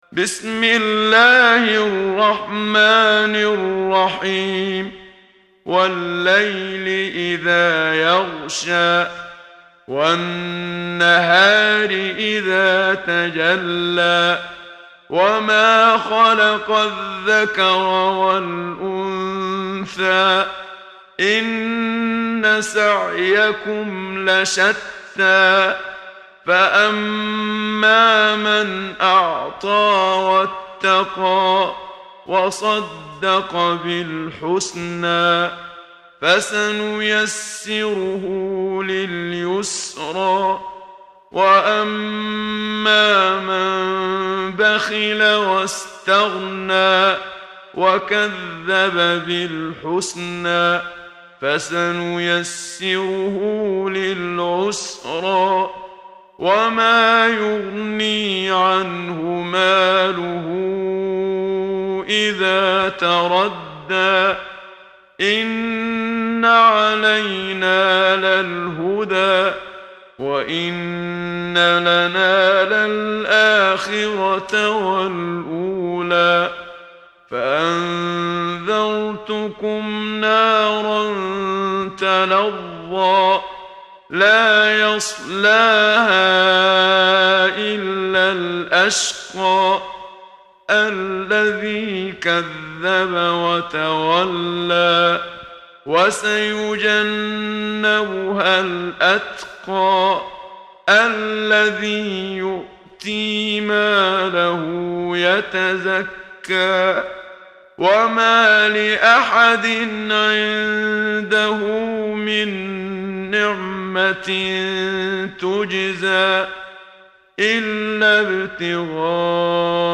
محمد صديق المنشاوي – ترتيل – الصفحة 8 – دعاة خير